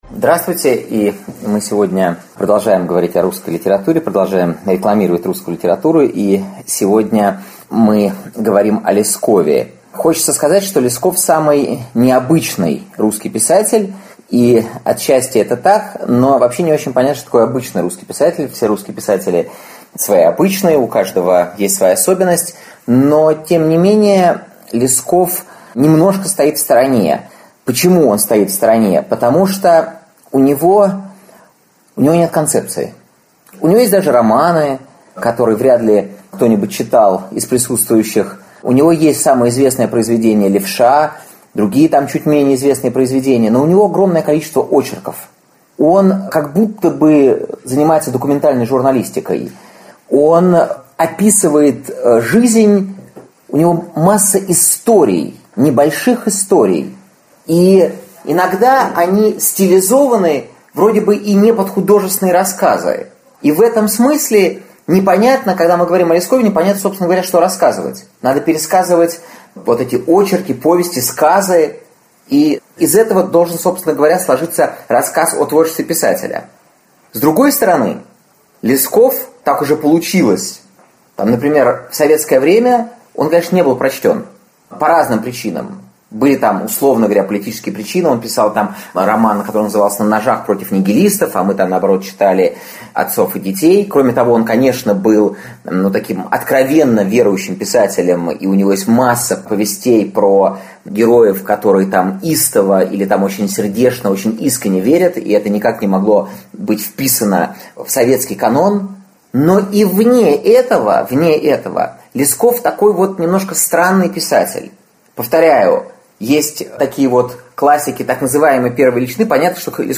Аудиокнига Зачем Левша испортил блоху, или судьба русских инноваций | Библиотека аудиокниг